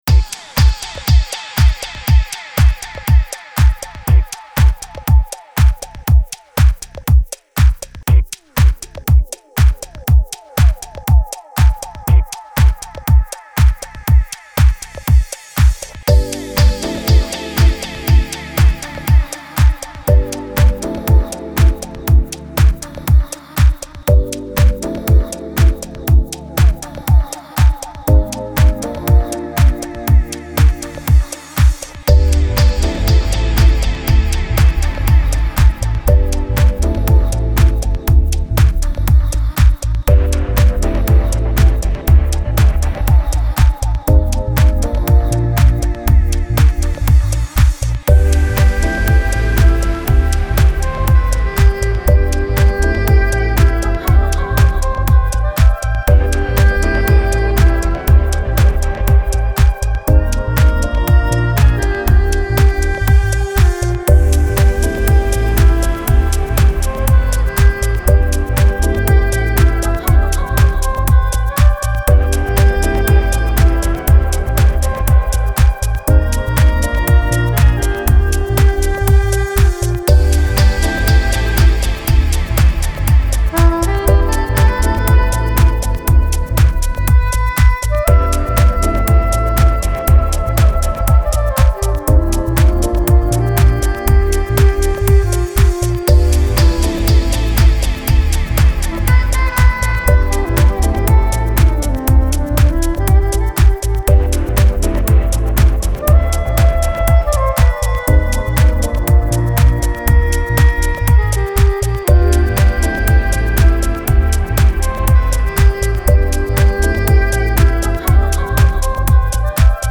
Genre: Chillout, Deep House.